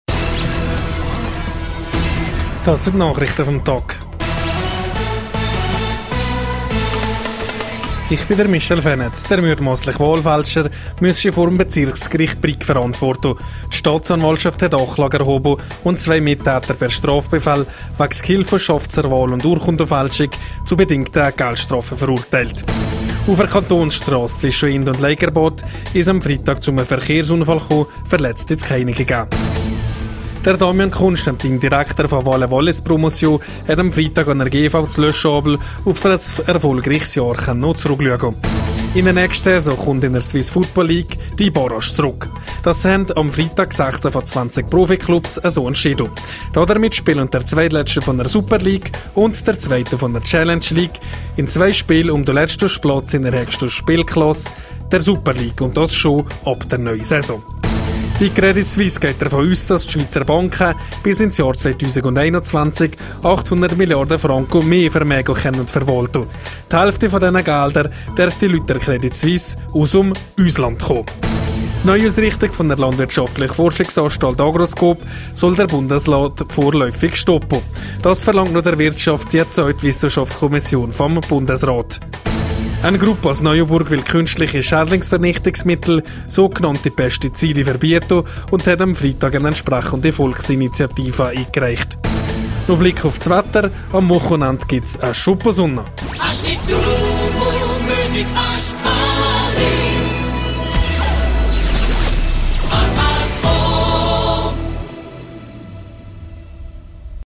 Nachrichte vam Tag (1.64MB)